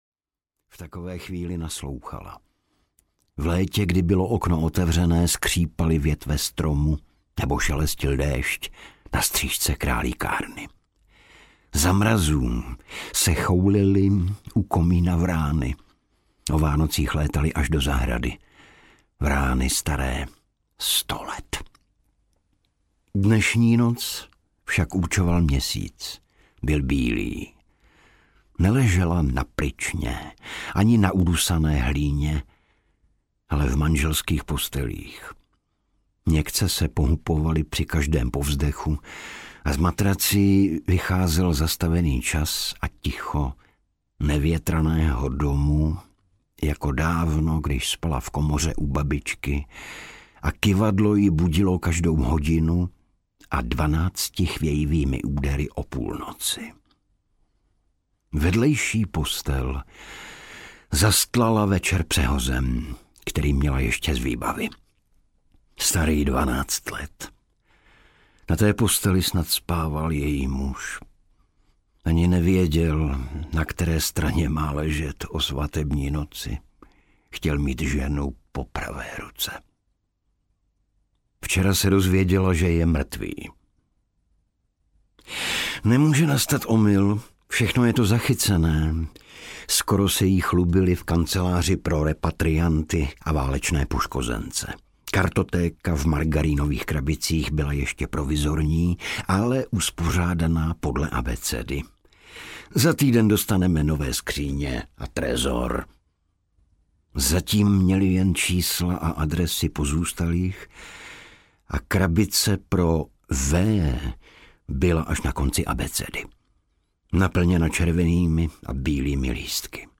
Střepiny v trávě audiokniha
Ukázka z knihy
• InterpretIgor Bareš